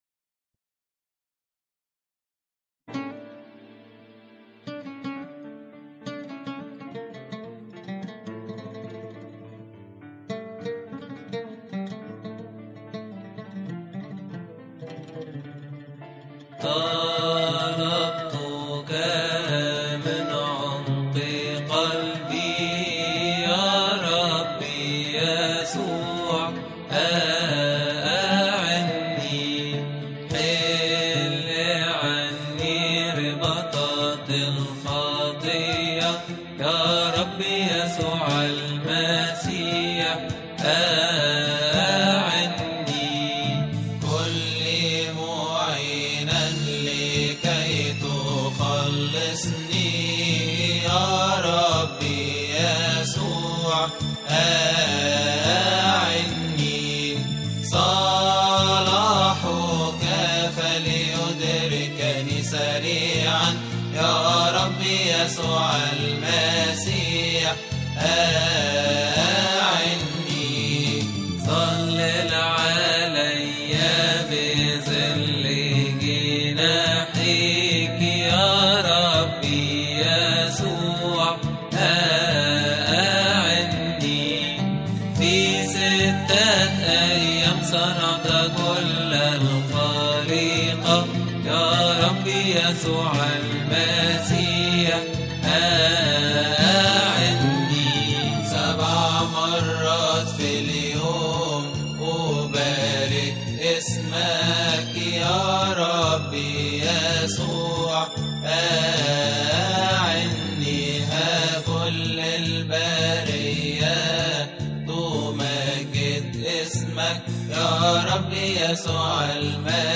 استماع وتحميل لحن طلبتك من عمق قلبى من مناسبة keahk